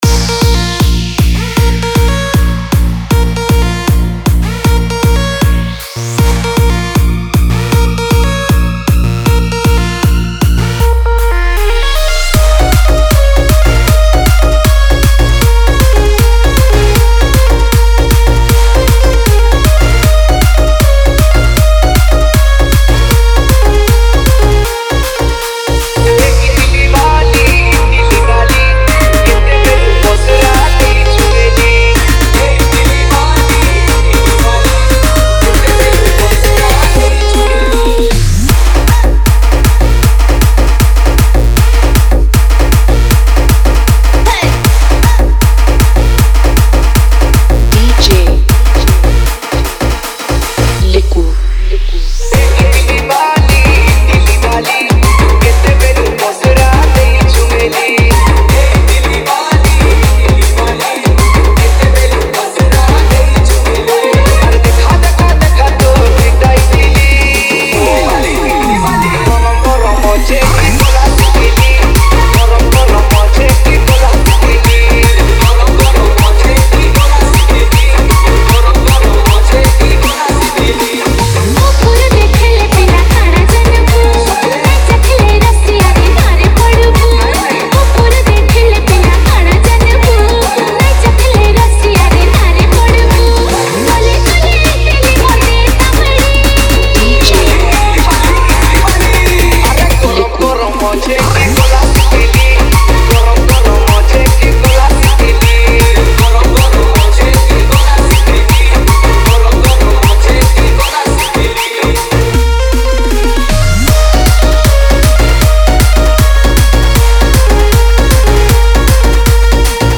Edm Tapori X Trance